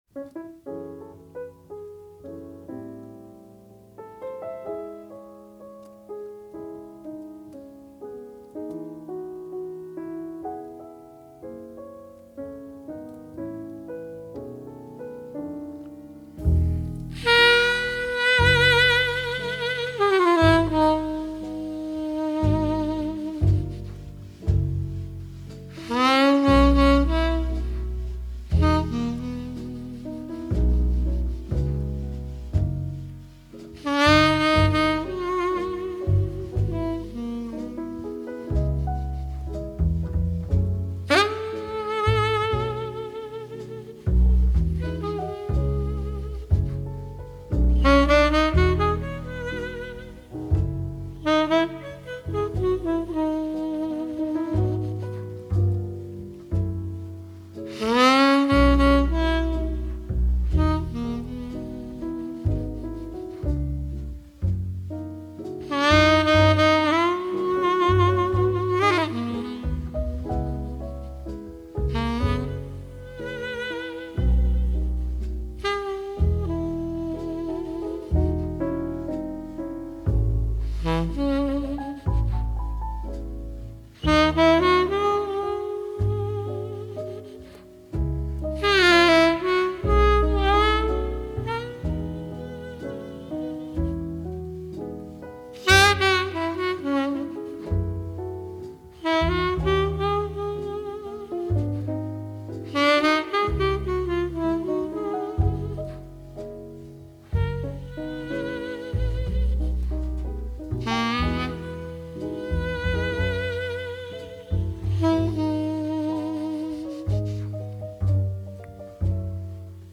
爵士及藍調 (544)
★ 如清流般柔和傾吐，撫慰人心的薩克斯風演奏！